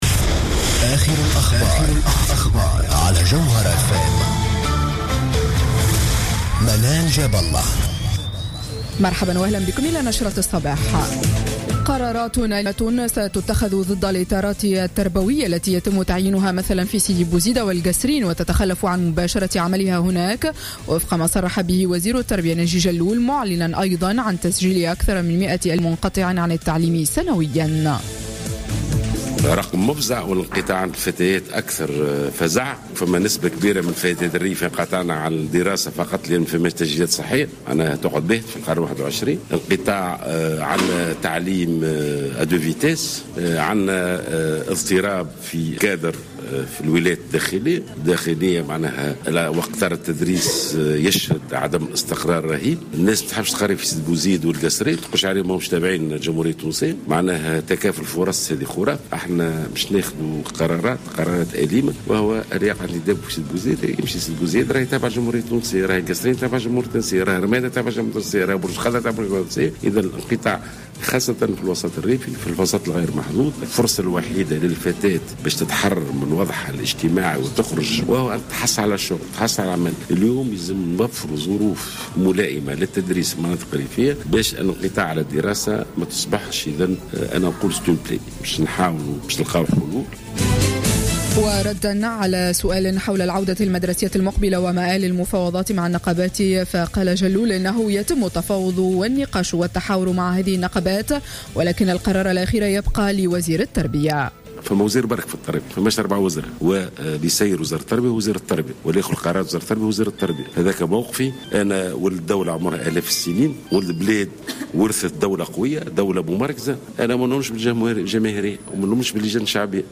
نشرة أخبار السابعة صباحا ليوم السبت 15 أوت 2015